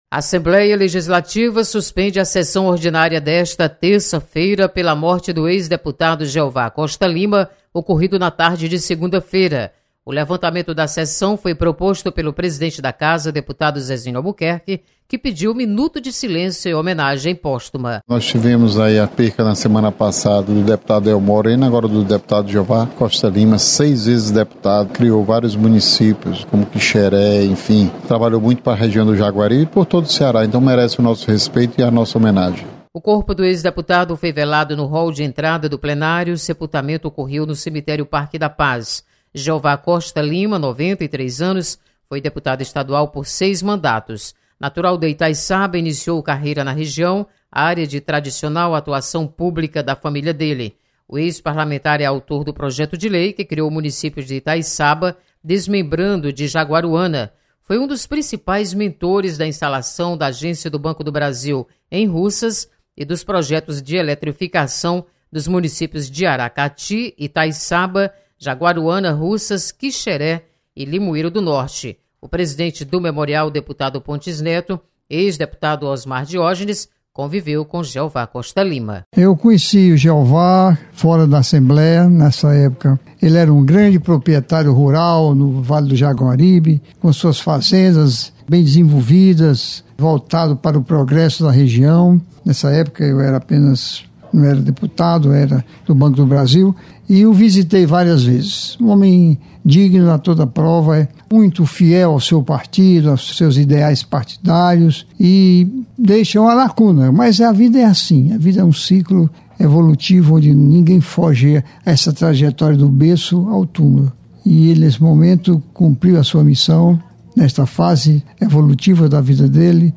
Sessão desta terça-feira é levantada em decorrência de falecimento do ex-deputado Jeová Costa Lima. Repórter